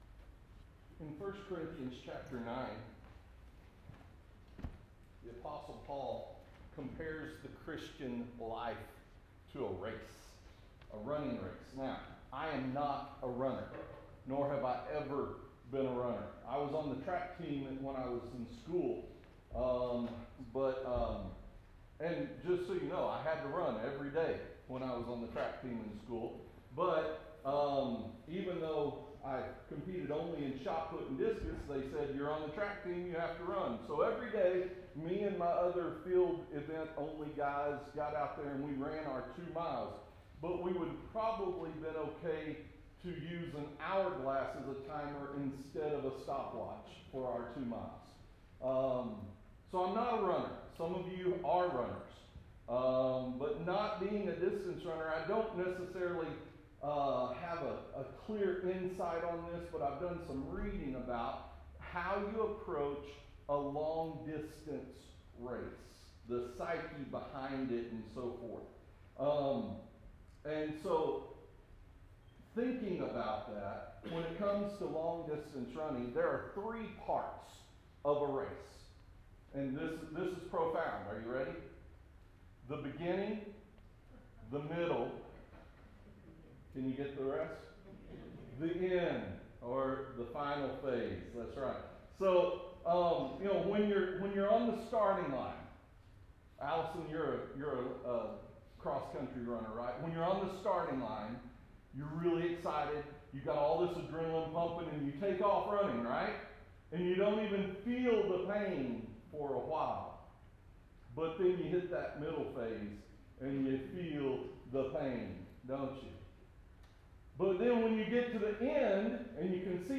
to join me here on stage to discuss what it looks like to find hope and strength for the daily grind.